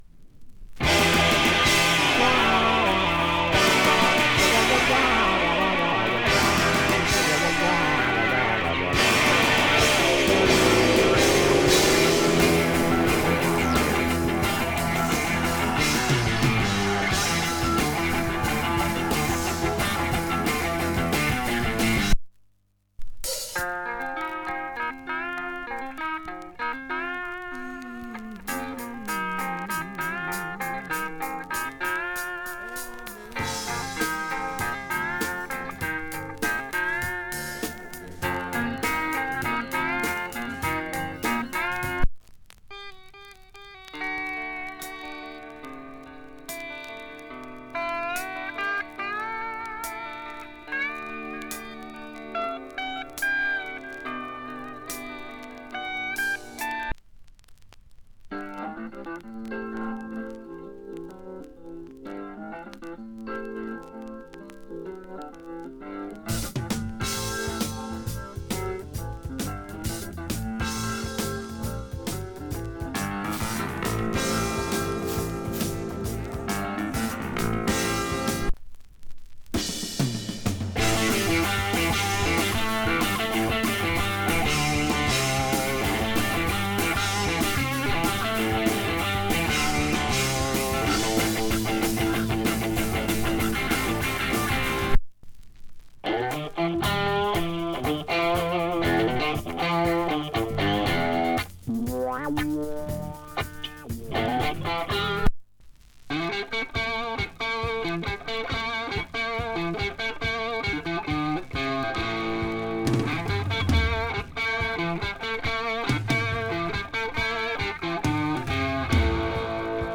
Жанр: PROG
пластинка играна но без дефектов, звучит NM.